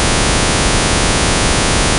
If the tones are very close to each other, that sounds like noise. And when the frequencies of the tones do have a ratio of 2 to the power of 1/12, it sounds like an organ.
The spectrum is not real noise, but consists of many tones.
Click here for an example WAV file, organ noise
12noiseorgan.wav